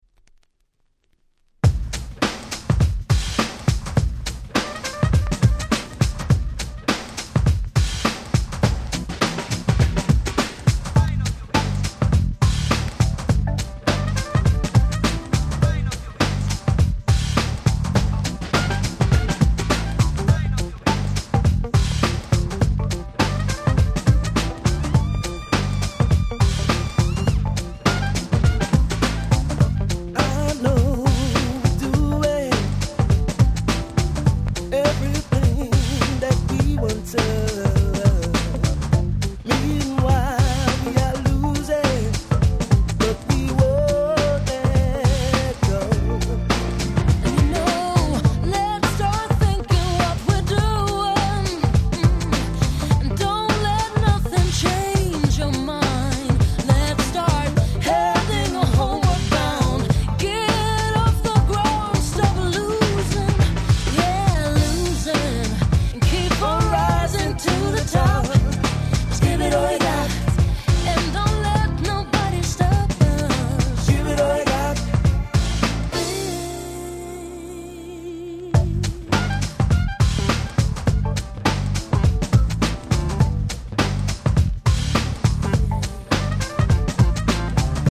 96' Nice Cover UK R&B !!
オシャレなCafeとかに似合っちゃう感じの凄〜く良いカバーです！